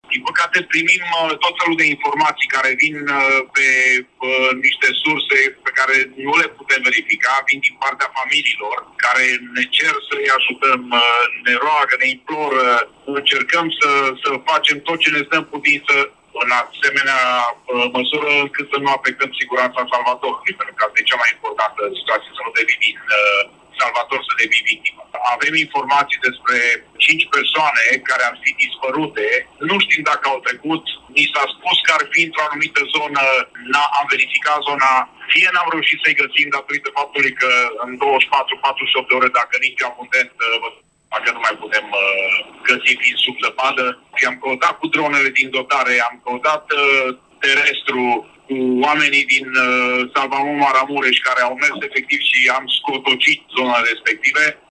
într-un interviu pentru corespondenta Europa FM